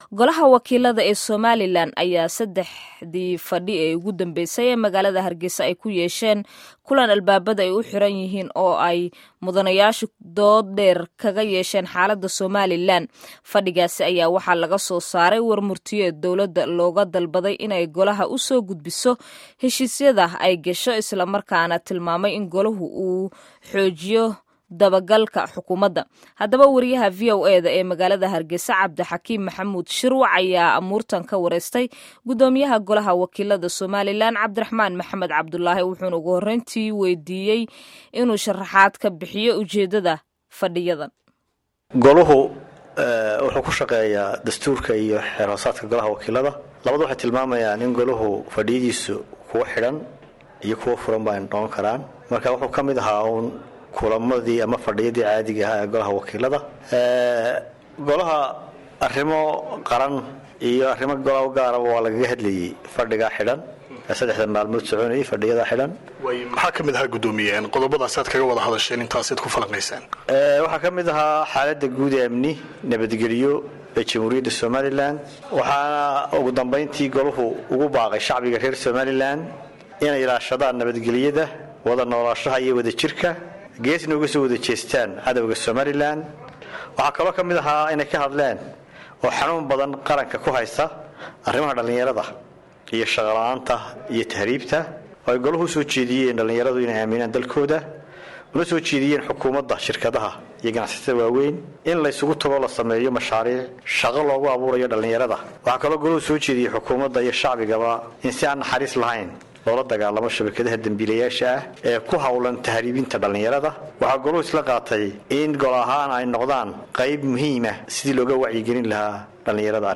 Wareysiga Golaha Wakiilada